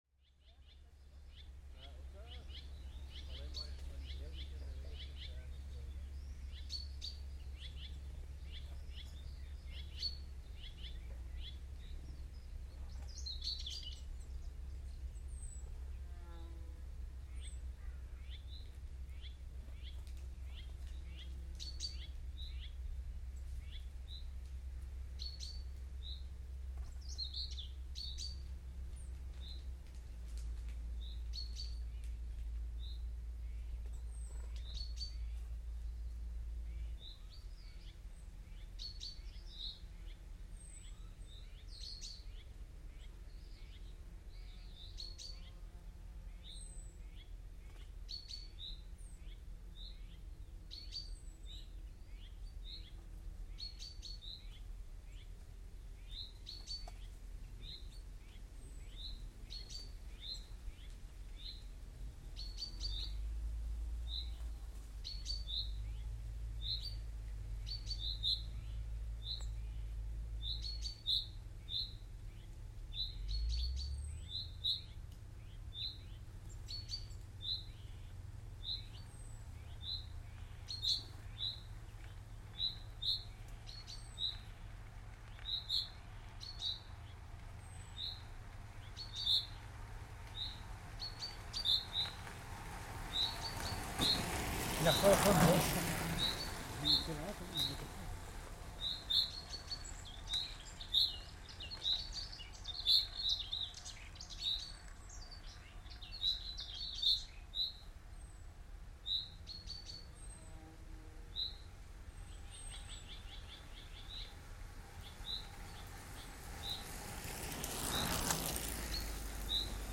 Some of them comment on my roadside equipment (Zoom F4, Nevaton MC59C ORTF)" Part of the StayHomeSounds project to map and reimagine the sounds of the Covid-19 lockdowns.